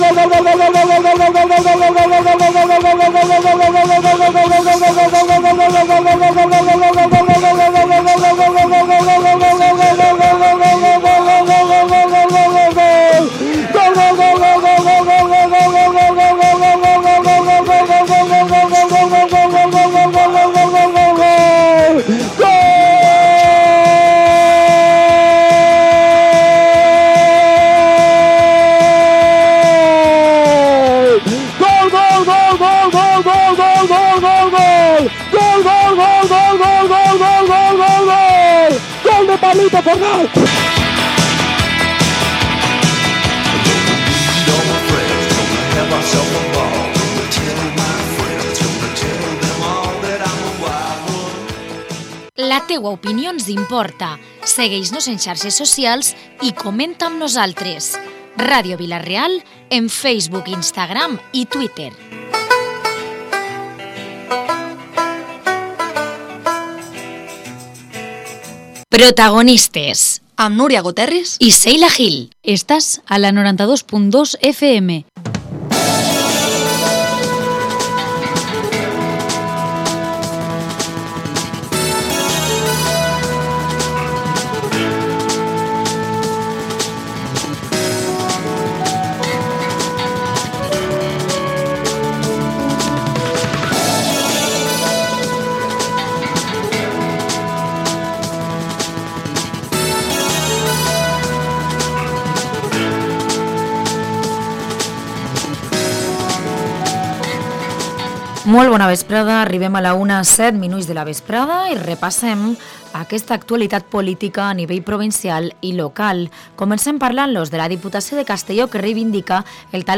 Hoy, en Protagonistes de Vila-real, hemos entrevistado a Llanos Massó, la presidente de VOX Castellón. También nos han visitado desde la asociación AFA para contarnos la programacion con motivo del próximo Día del Alzheimer. El alcalde de Betxí, Alfred Remolar, nos habla sobre las actividades de las fiestas de la localidad que se están celebrando esta semana.